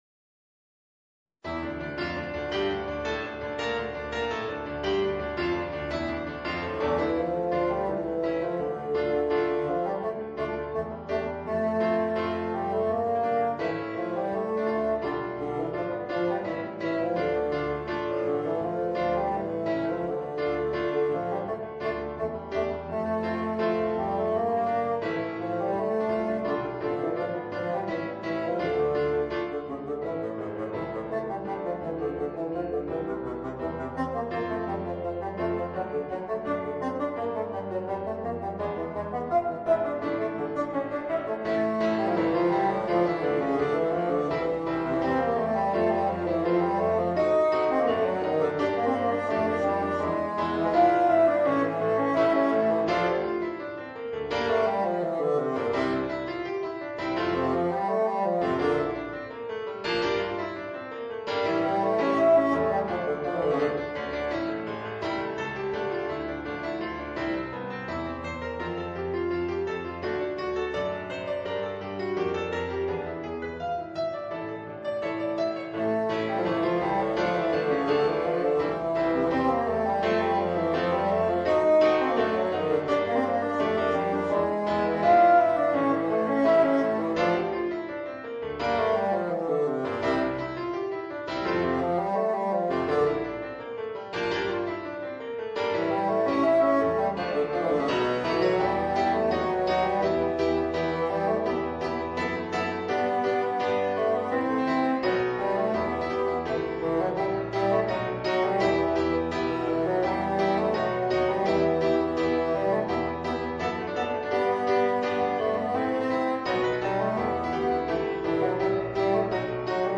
Voicing: Bassoon and Piano